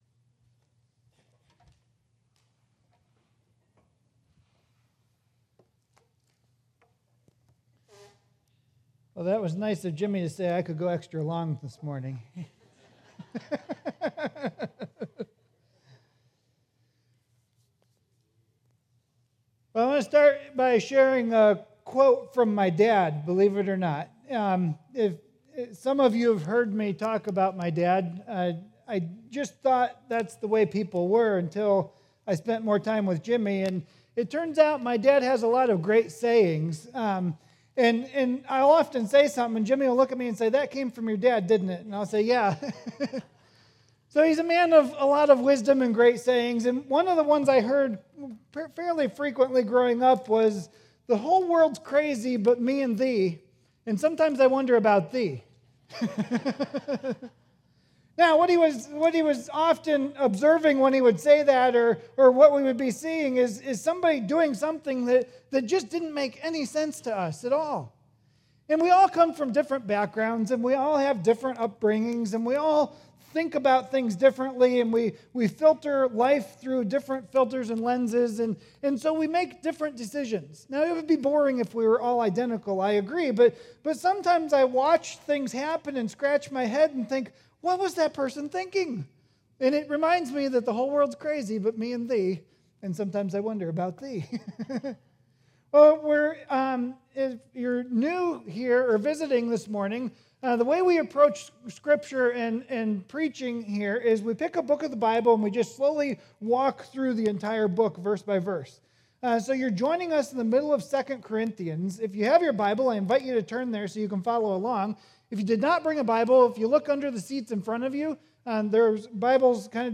Sermons | Grace Fellowship Church